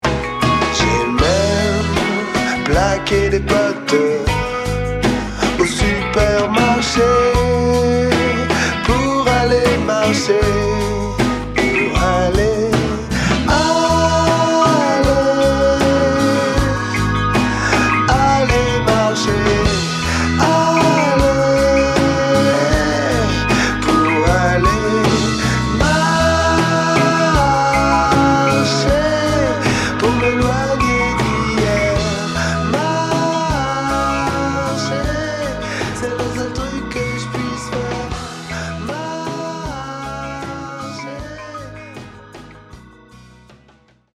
Folk
Soul